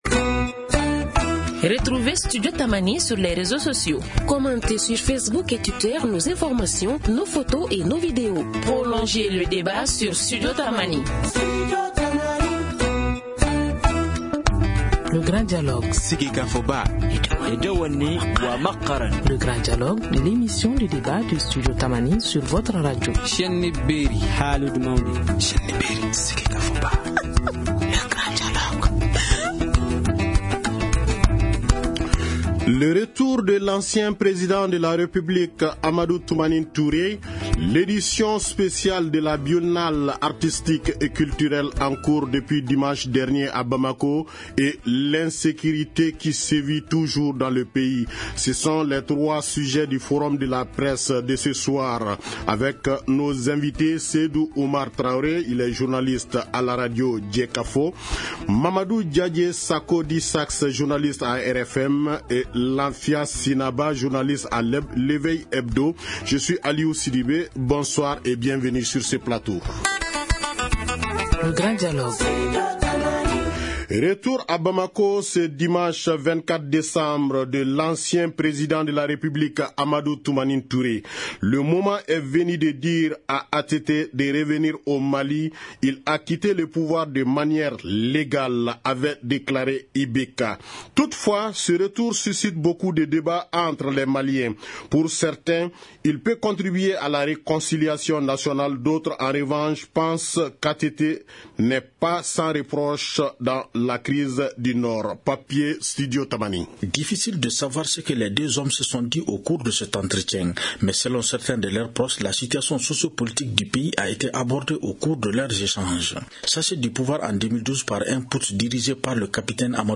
Nos invités (tous journalistes) :